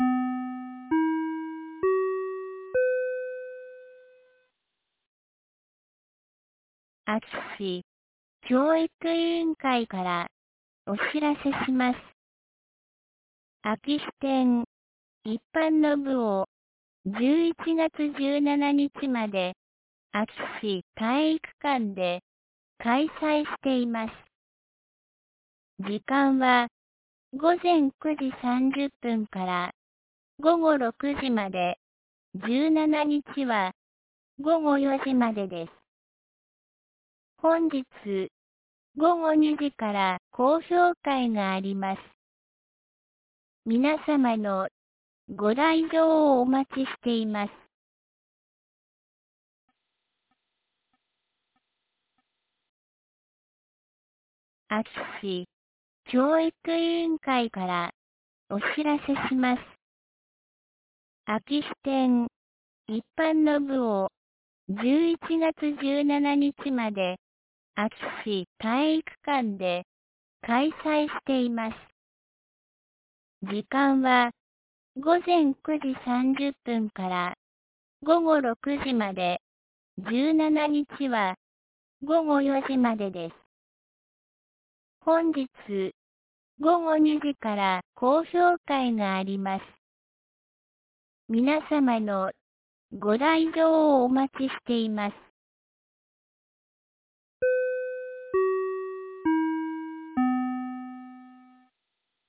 2024年11月09日 12時11分に、安芸市より全地区へ放送がありました。